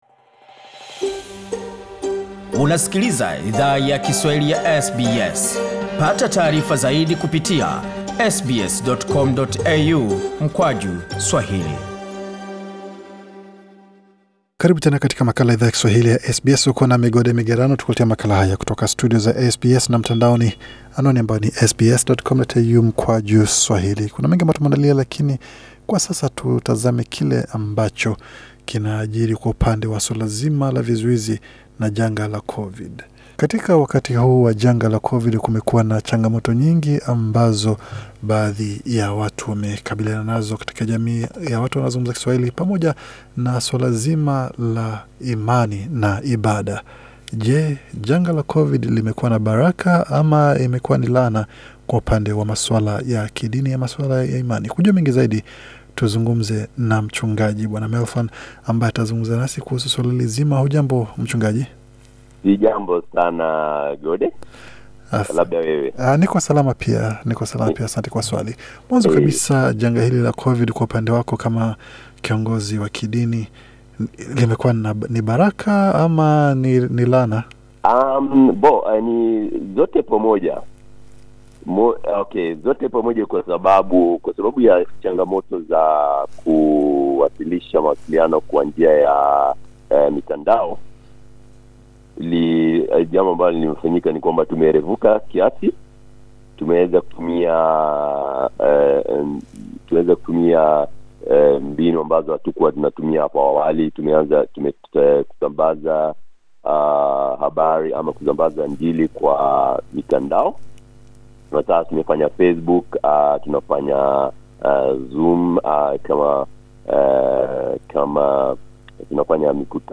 Katika mazungumzo maalum na idhaa ya Kiswahili ya SBS